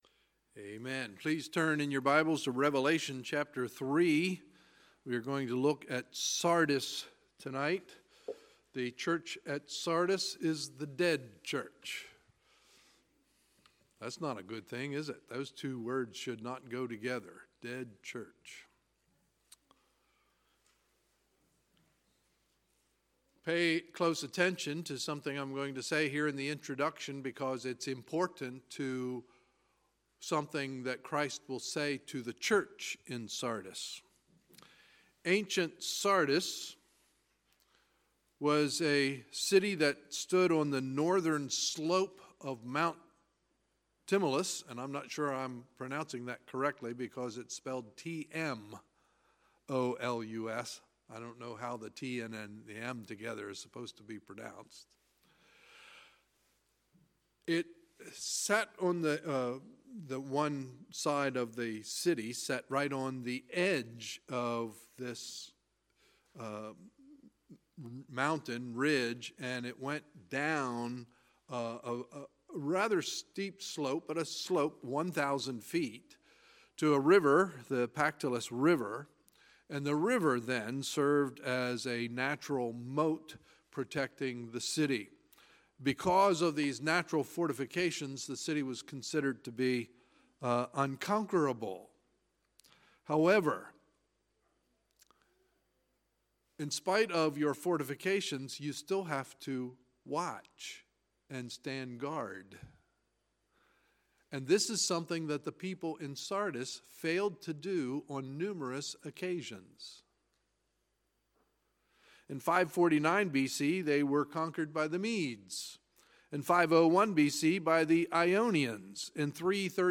Sunday, March 25, 2018 – Sunday Evening Service